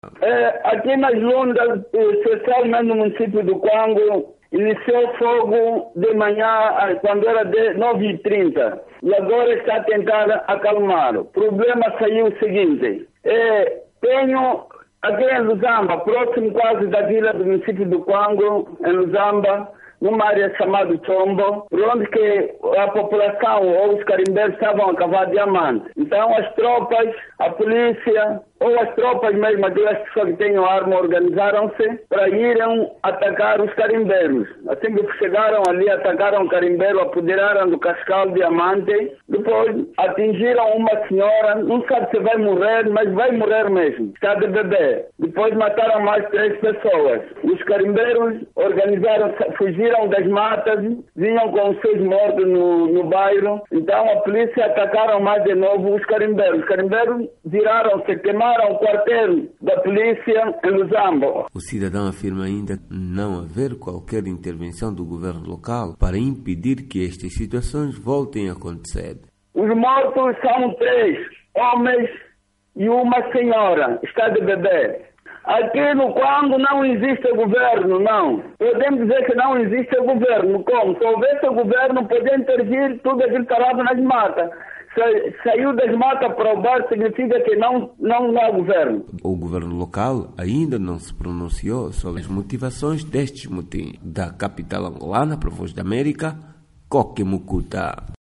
residentes na Lunda Norte falam sobre confrontos com a policia - 1:38